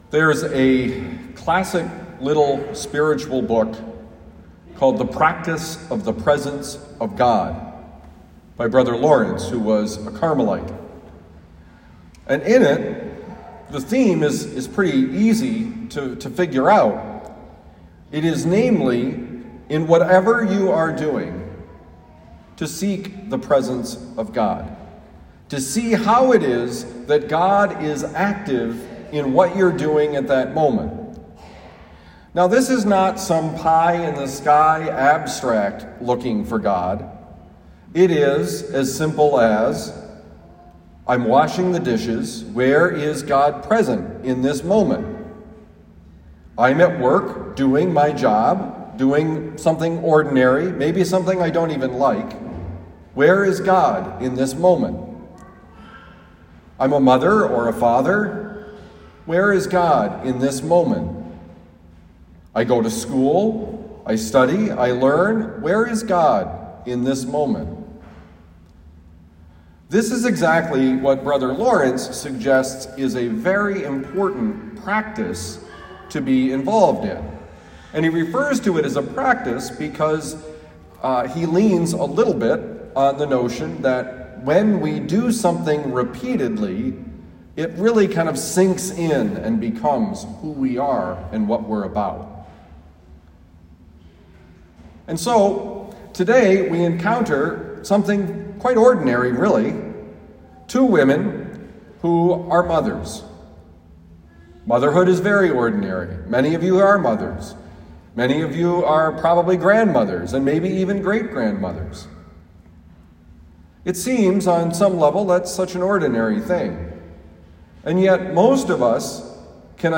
Homily given at Our Lady of Lourdes Parish, University City, Missouri.
homily